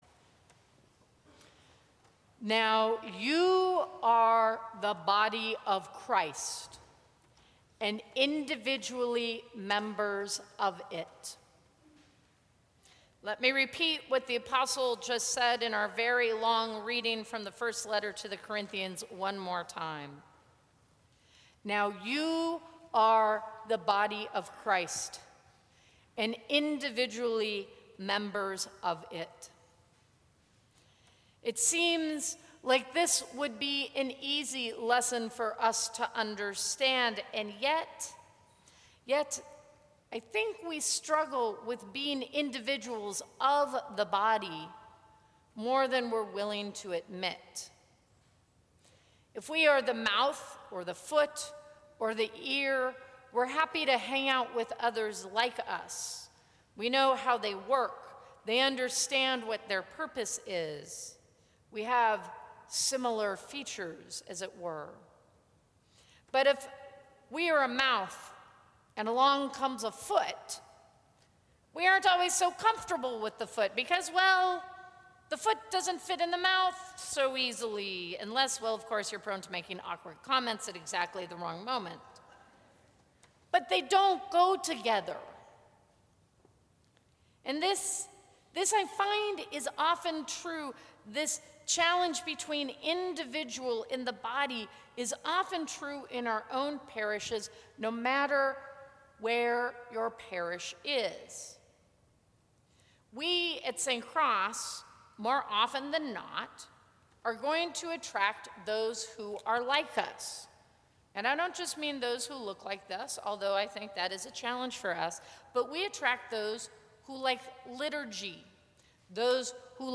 Sermons from St. Cross Episcopal Church The Body of Christ Jan 25 2016 | 00:11:31 1x 00:00 / 00:11:31 Subscribe Share Apple Podcasts Spotify Overcast RSS Feed Share Link Embed